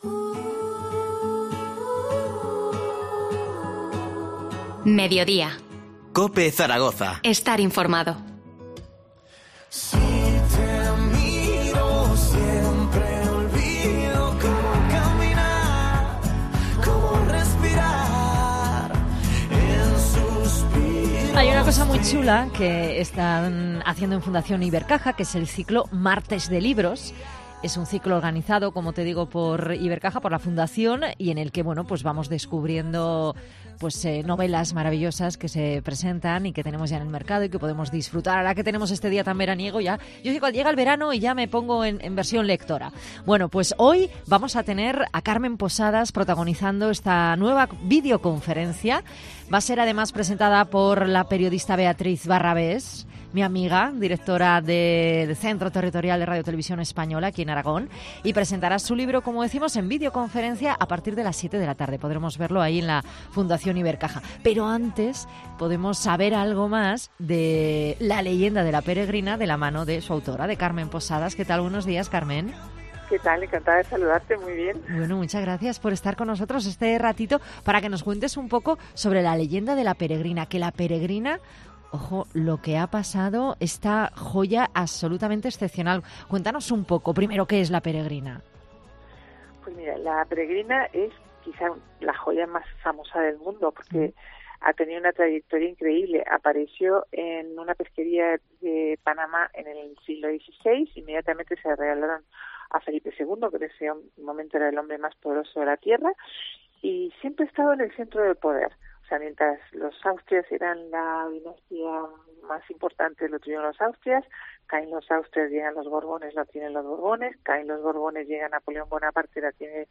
Entrevista a Carmen Posada, que participa en 'Martes de Libros', de Fundación Ibercaja. 18-05-21